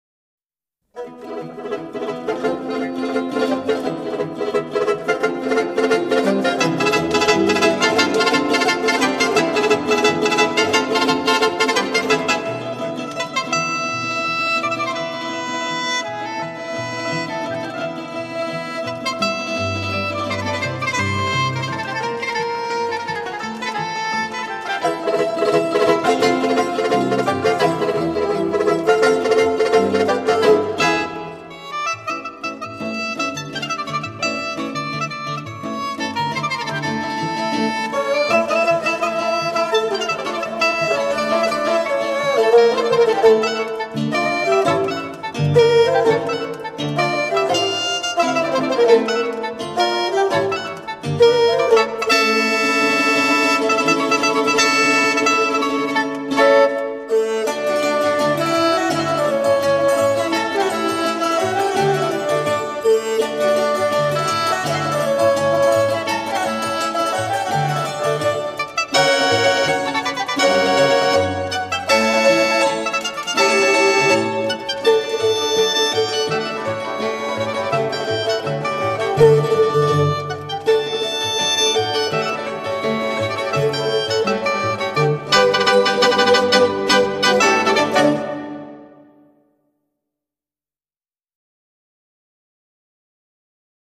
录音棚：中央电视台480平方米录音棚
游走于流行与古典的边缘，引领风潮，独树一帜！